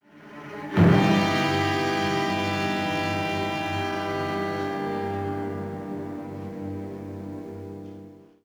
Suspense 1 wav